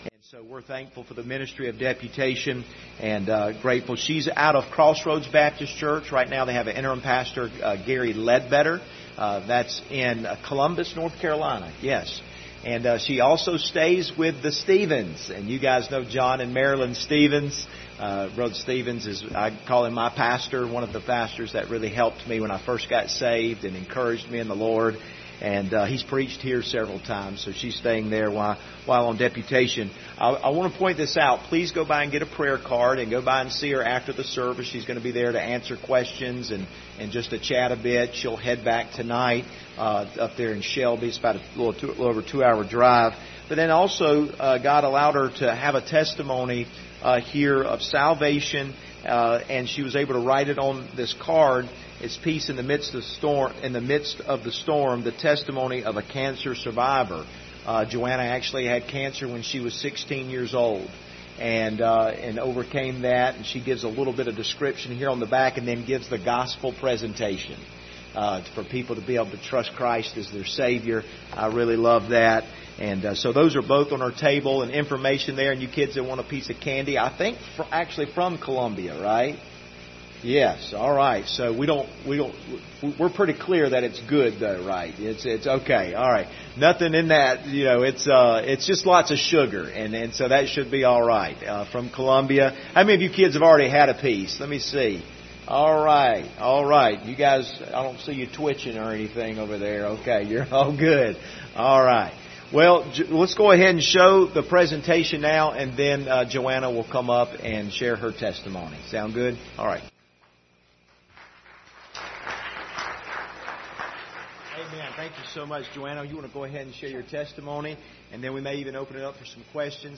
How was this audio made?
General Passage: 1 Thessalonians 2:17-20 Service Type: Sunday Evening Topics